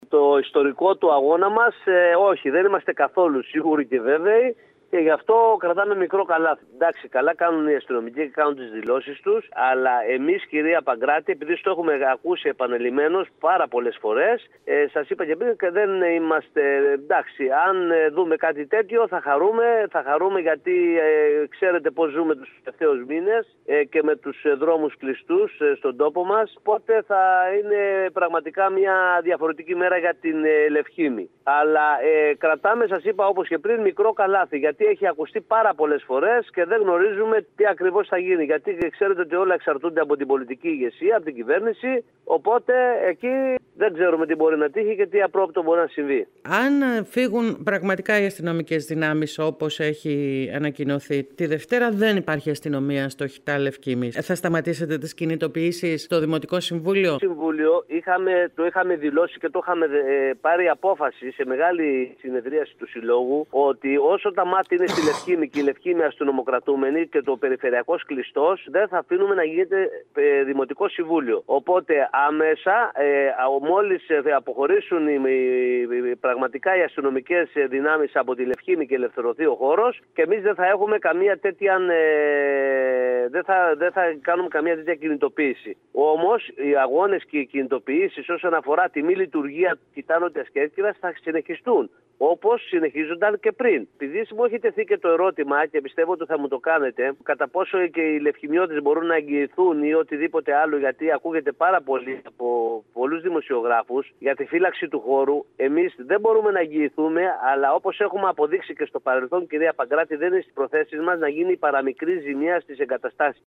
μιλώντας σήμερα στο σταθμό μας.